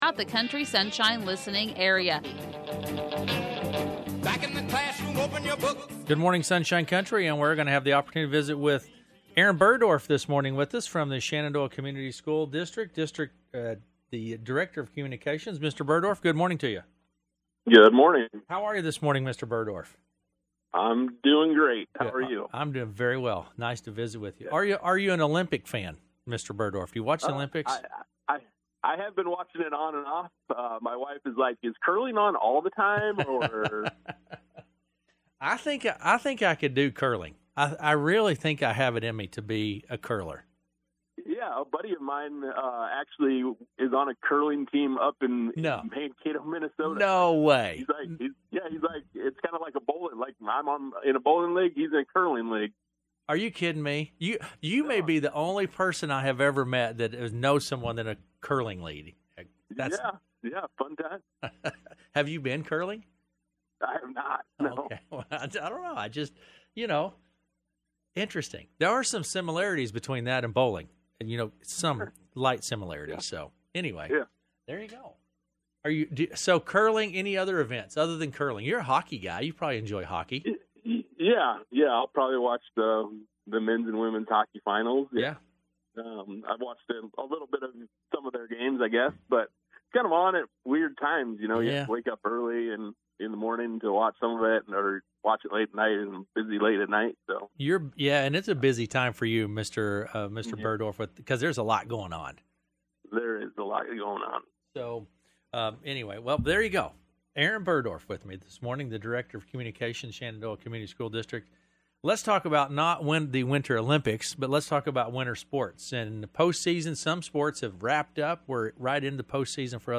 joined KCSI/KOAK for the weekday school update